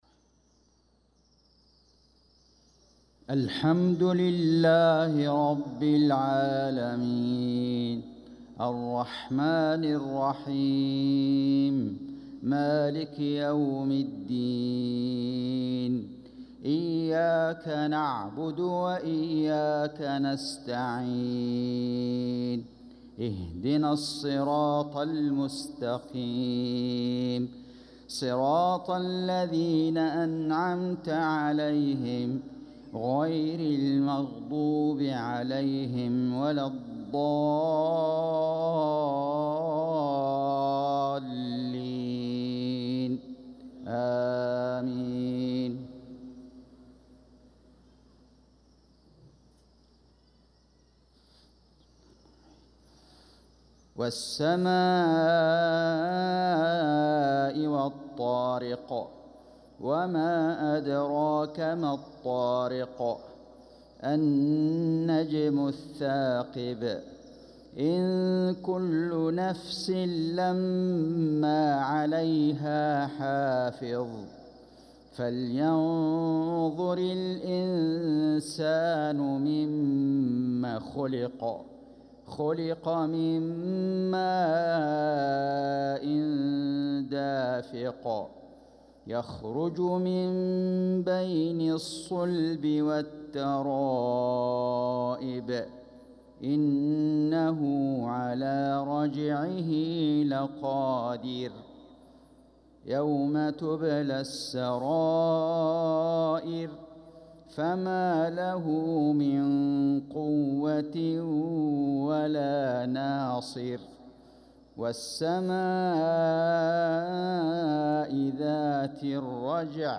صلاة المغرب للقارئ فيصل غزاوي 10 صفر 1446 هـ